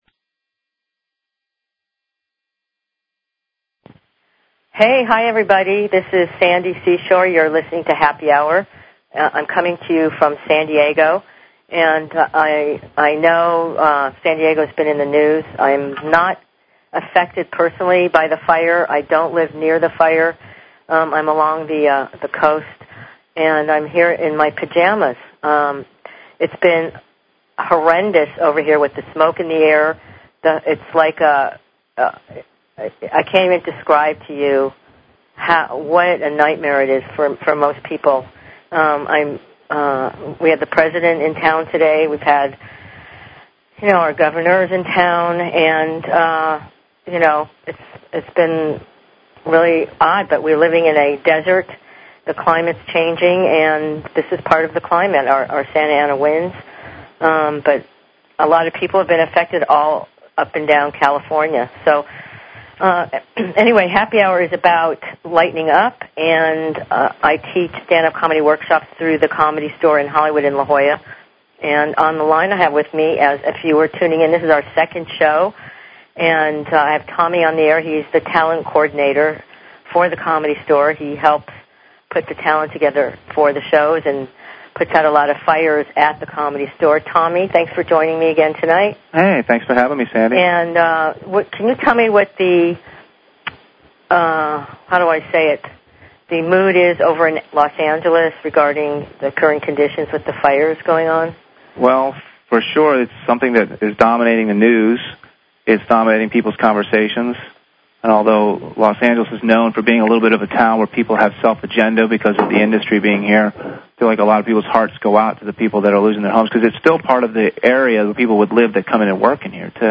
Talk Show Episode, Audio Podcast, Happy_Hour_Radio and Courtesy of BBS Radio on , show guests , about , categorized as